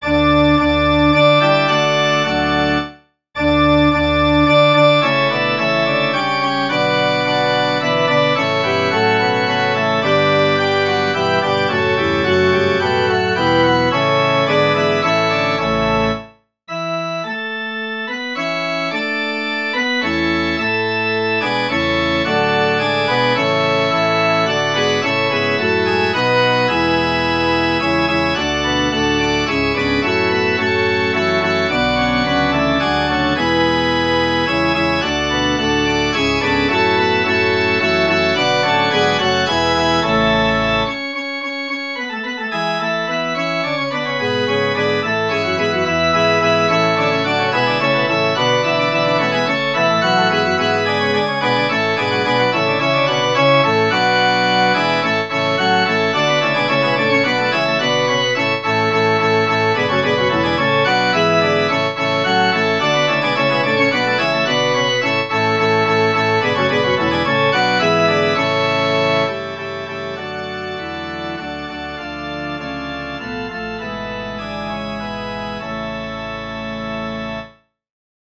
Tonalité : ré (centré autour de)